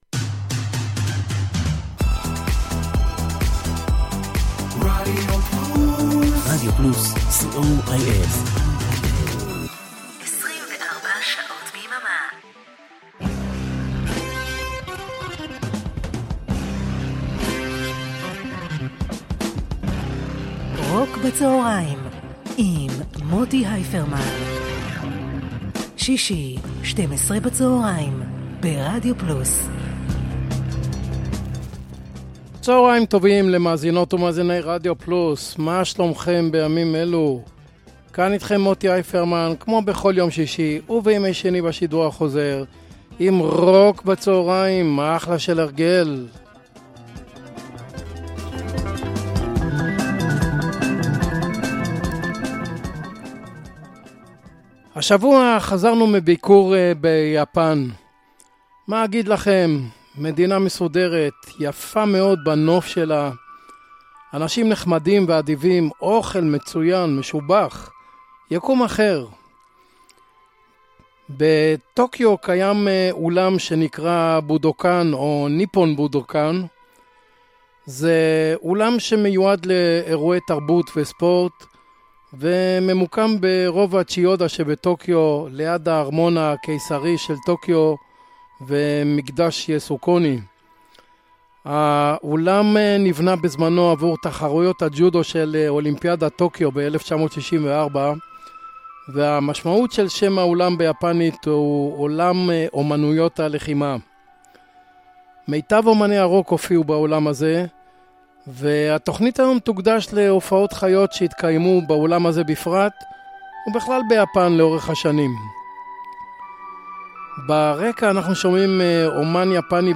blues rock
classic rock
pop rock